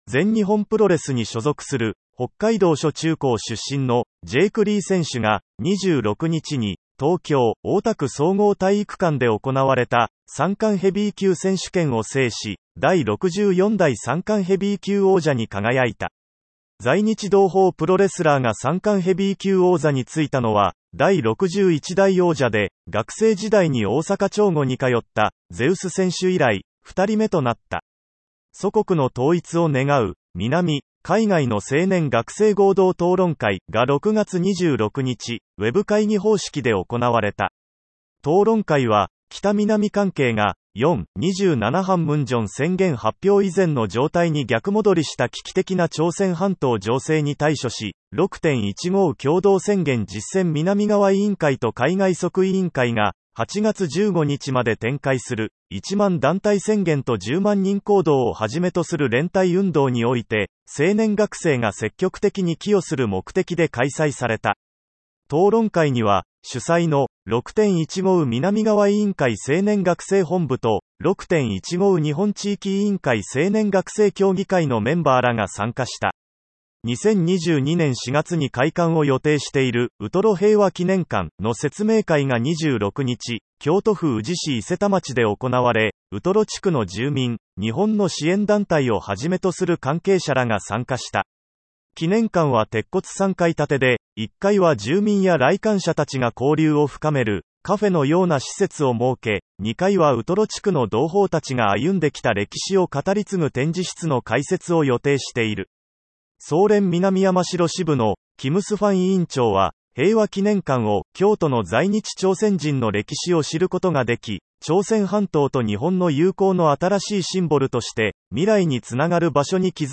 「聴くシンボ」は、朝鮮新報電子版 DIGITAL SINBOのニュースを音声でお聞きいただけます。同サービスでは、1週間の主要ニュースをピックアップし、毎週日曜日にダイジェストでお届けします。
※音声読み上げソフトを導入しているため、音声ニュースの中で発音が不自然になることがあります。